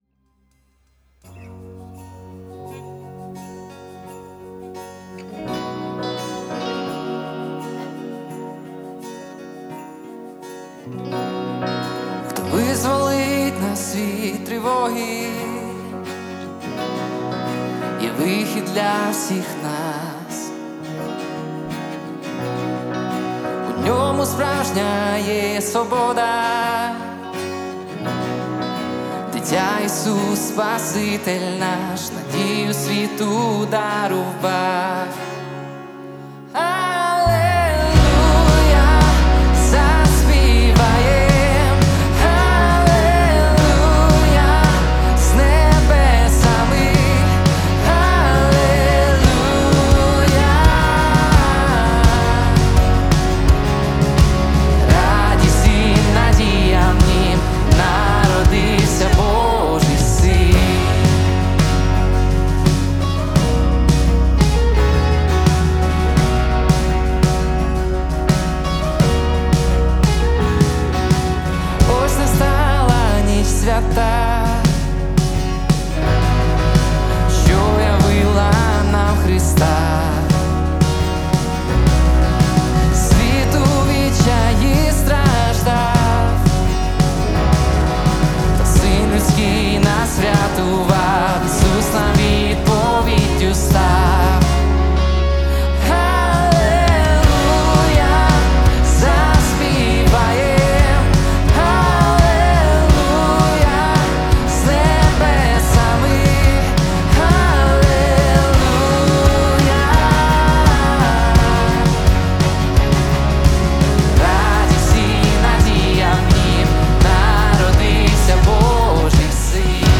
179 просмотров 26 прослушиваний 2 скачивания BPM: 85